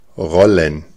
Ääntäminen
Ääntäminen Tuntematon aksentti: IPA: /ˈʀɔlən/ IPA: /ˈʁɔ.lən/ Haettu sana löytyi näillä lähdekielillä: saksa Käännöksiä ei löytynyt valitulle kohdekielelle.